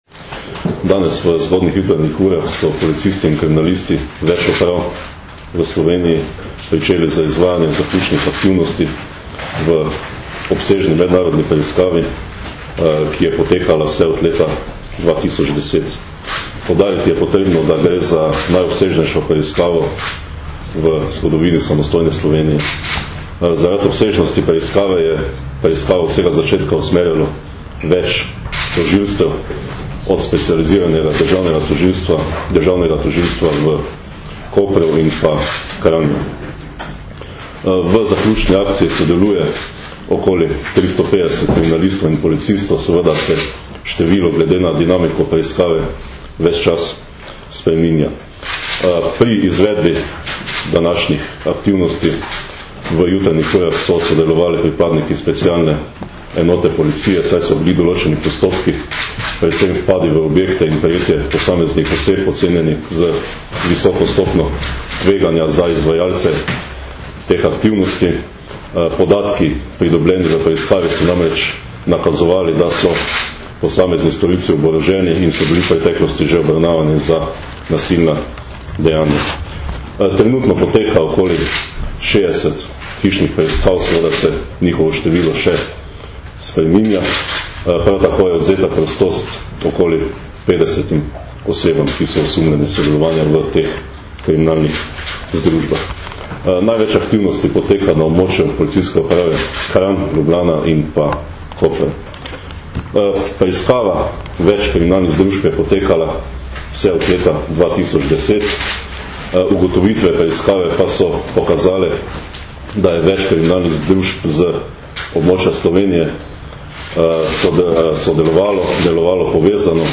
Zvočni posnetek izjave Marjana Fanka, pomočnika direktorja Uprave kriminalistične policije (mp3)